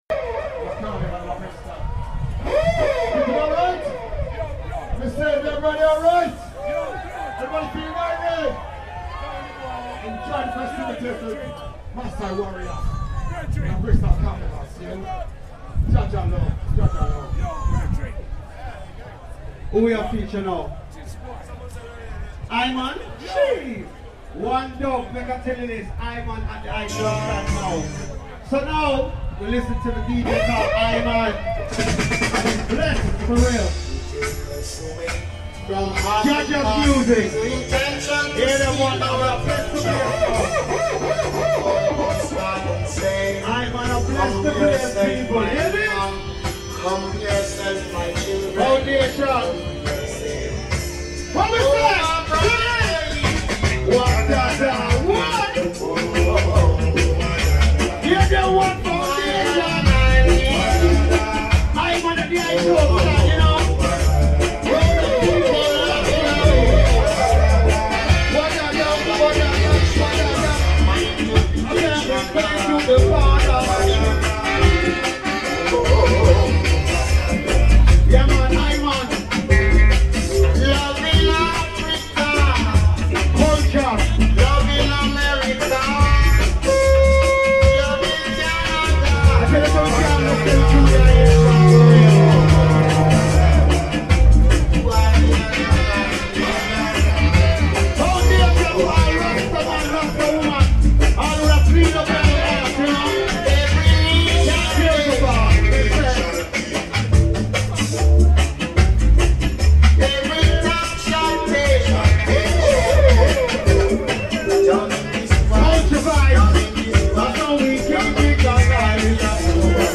All day session at Malcolm X Centre in Bristol